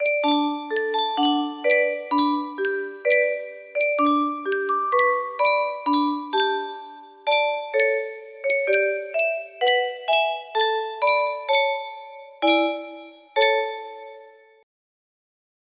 Yunsheng Custom Tune Music Box - Denmark National Anthem music box melody
Full range 60